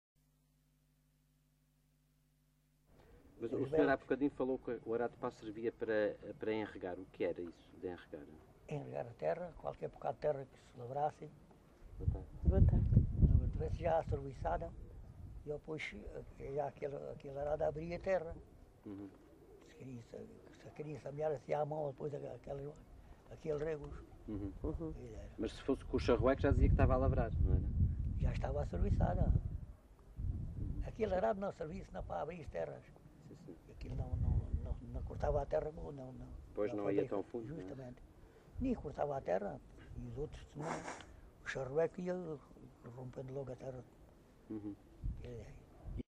LocalidadeCabeço de Vide (Fronteira, Portalegre)